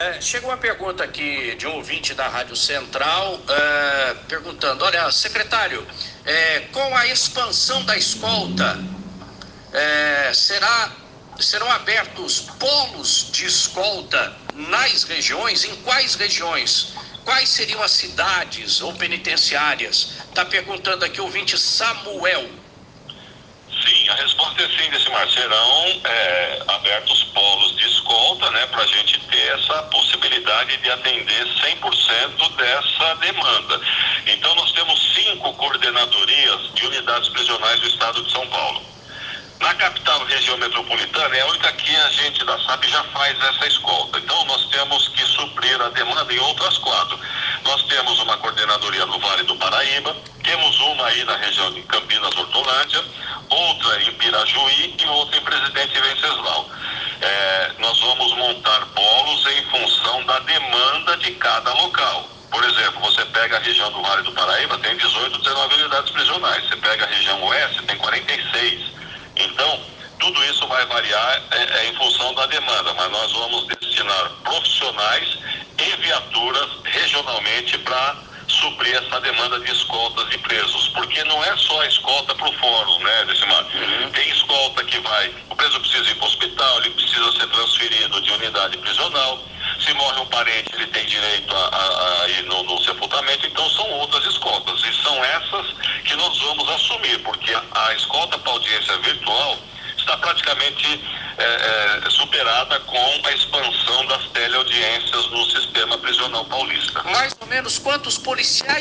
Em entrevista a rádio Jovem Pan, Coronel Restivo confirma implantação da escolta e compra de 132 viaturas
Em entrevista na manhã de ontem, 26 de maio, na rádio Jovem Pan Litoral, o Secretário Coronel Restivo confirmou a implantação da escolta de presos por todo o Estado de São Paulo realizada pelos Policiais Penais AEVPs e também a compra de 132 viaturas;
Áudio do Secretário falando dos Polos de Escolta que serão implantados;